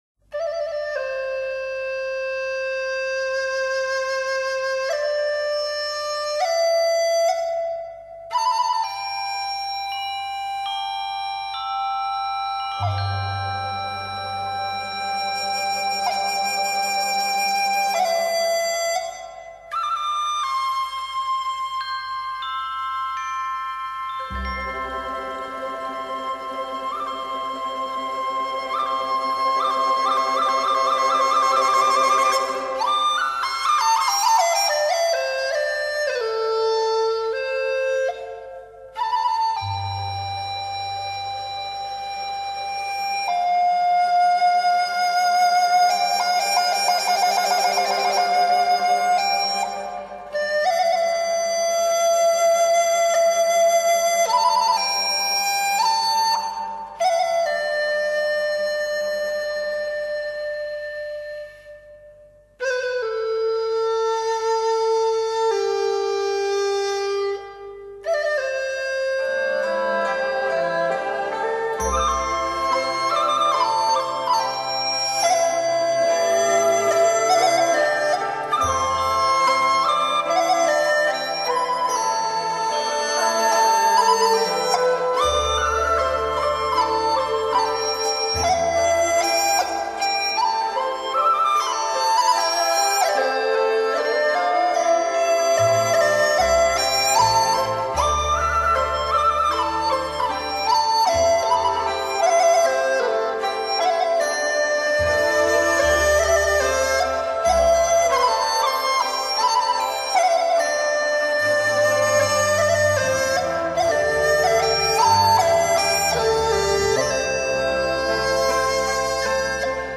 演奏水准超一流的
笛子